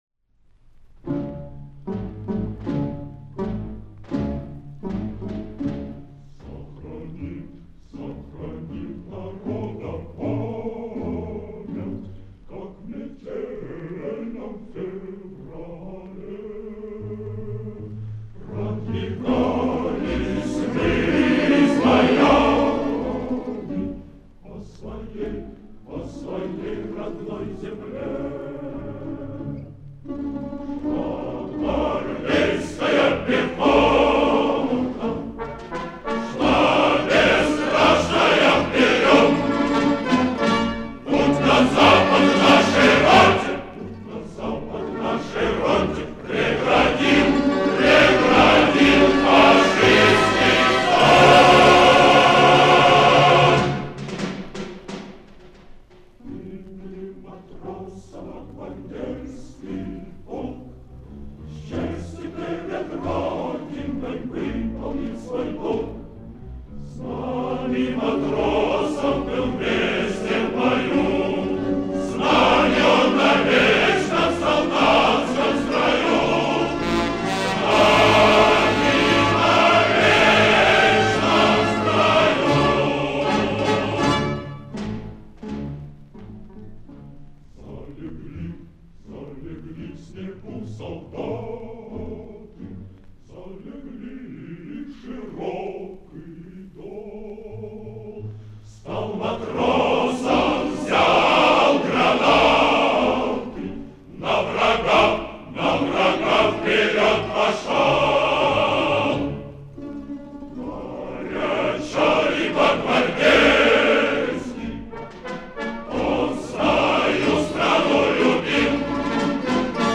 Исполнение, вероятно, современно написанию песни.